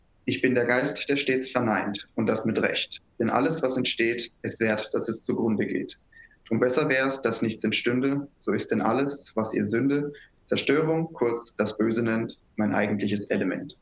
Die Aufnahmen wurden in verschiedenen Abständen aufgenommen.
Ein Hinweis: Die Aufnahmen wurden in einem akustisch gut ausgestatteten Videokonferenzraum aufgenommen.
Abstand 200 cm - Mikrofon auf Sprecher gerichtet